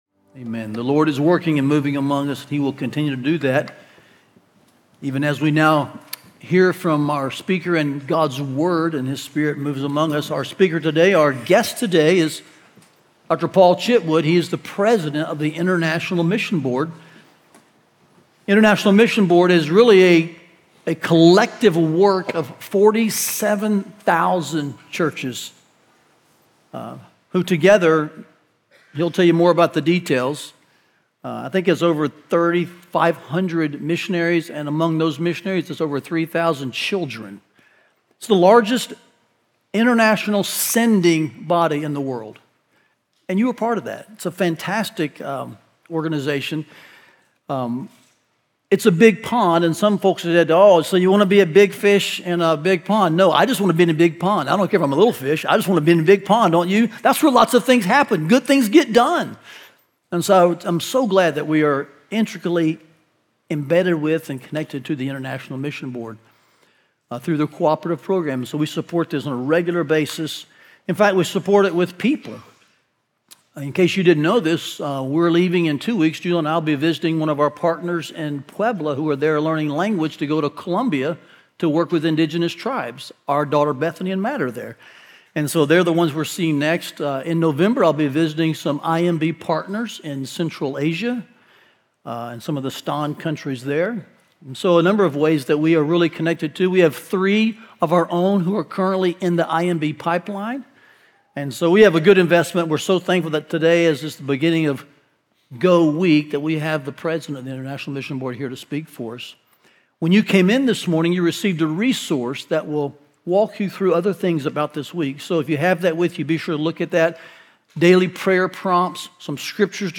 Every person is lost in sin, but through Jesus Christ, God provided the only solution to our universal and eternal need. Listen to this year's GO Week sermon and be challenged and reminded of the hope found only in the gospel.